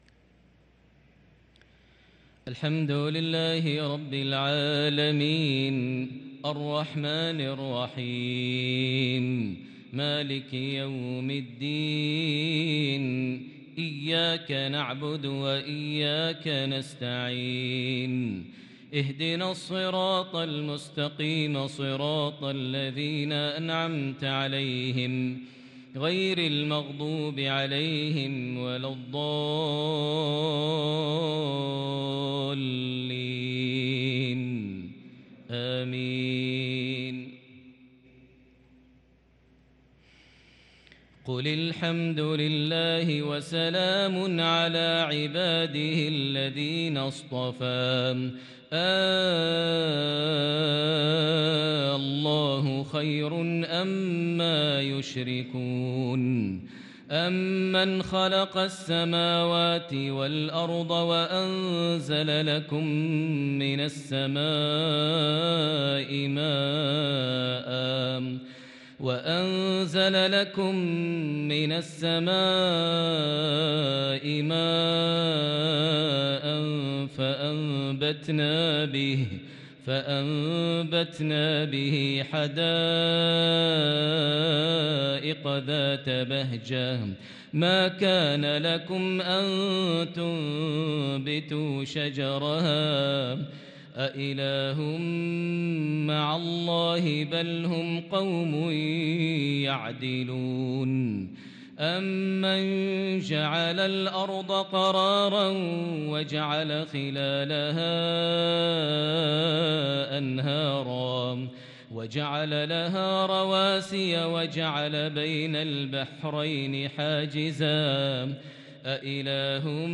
صلاة العشاء للقارئ ماهر المعيقلي 10 صفر 1444 هـ
تِلَاوَات الْحَرَمَيْن .